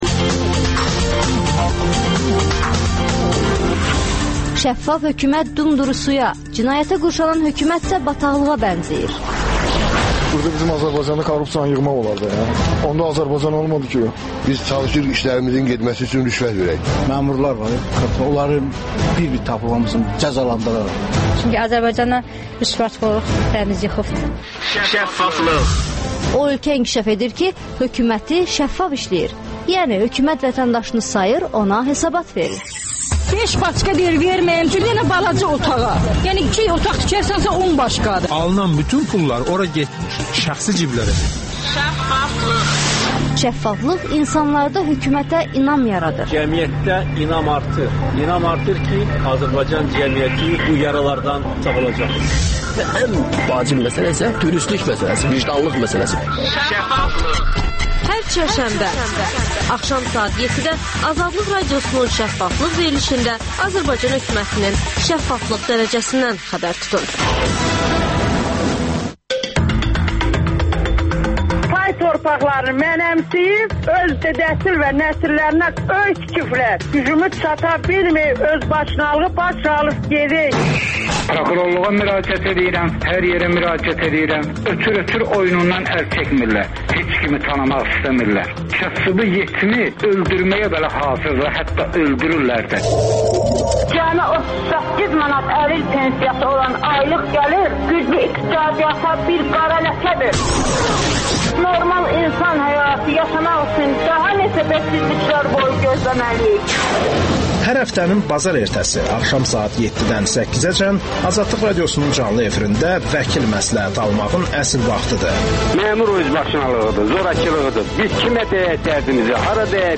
«Ziyalı mövqeyi», «ziyalı borcu» deyilən anlayışa necə yanaşmaq lazımdır? Azadlıq Radiosunun «Pen klub» proqramındakı debata tanınmış yazar və alimlər qatıldılar.